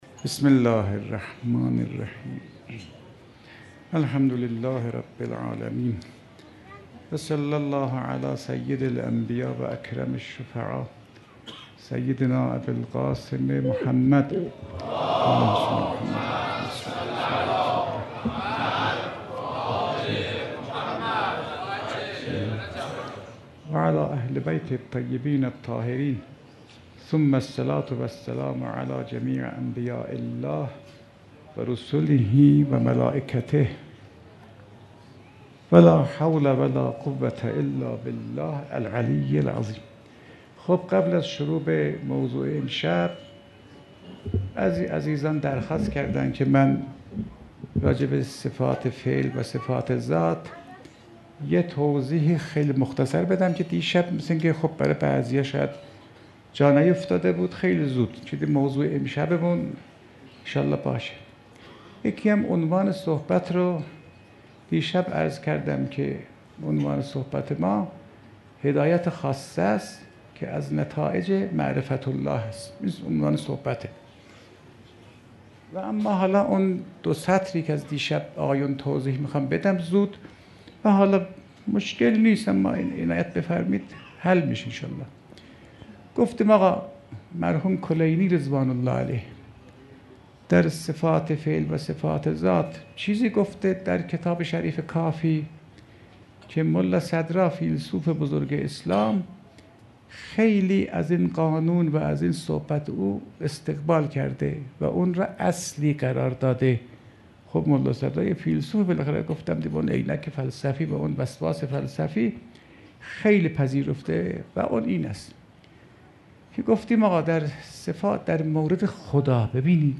سخنرانی آیت‌الله فاطمی‌نیا سال 98
گلچین بهترین سخنرانی های حجت الاسلام فاطمی‌نیا